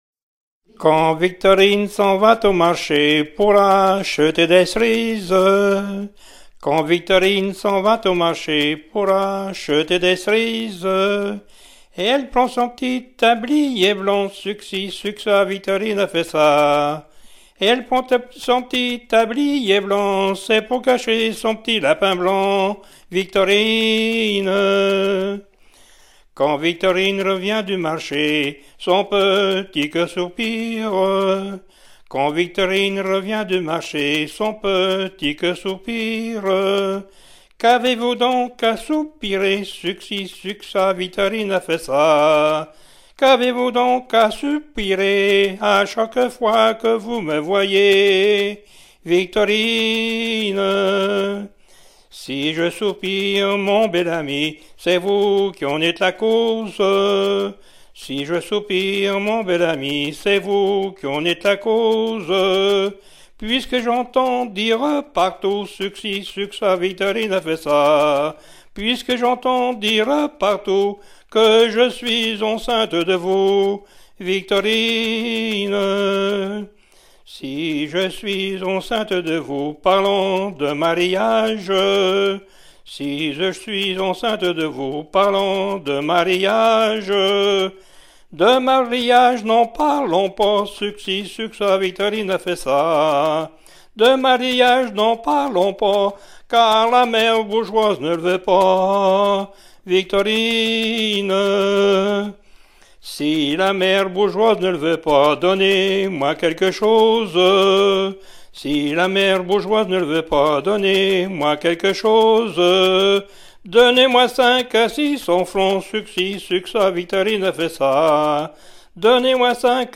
Patois local
Genre strophique
Pièce musicale éditée